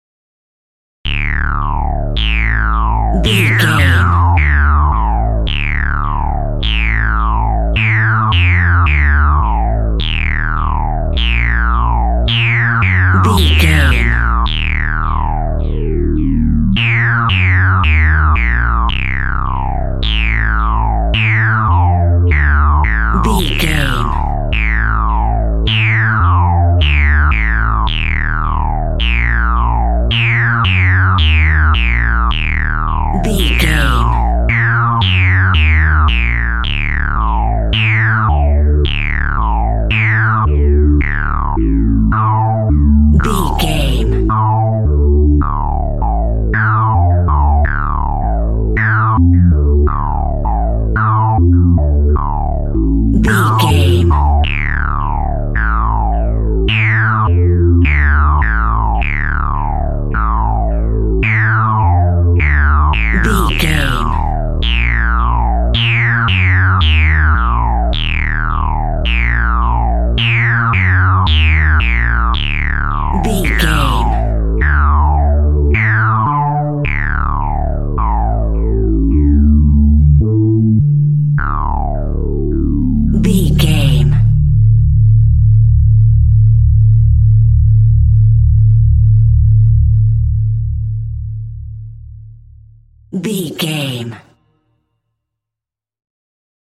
Horror Synths in Space.
Aeolian/Minor
E♭
ominous
dark
haunting
eerie
synthesizer
Horror Ambience
electronics